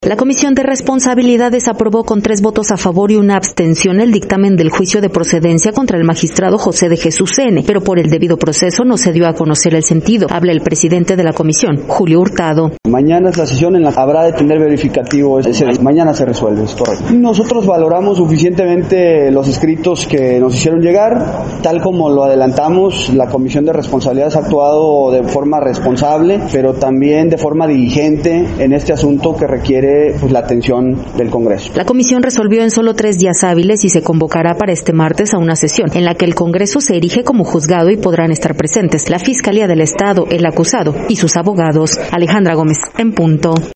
La Comisión de Responsabilidades, aprobó con tres votos a favor y una abstención, el dictamen del Juicio de Procedencia contra el magistrado José de Jesús N, pero por el debido proceso no de dio a conocer el sentido. Habla el presidente de la comisión, Julio Hurtado: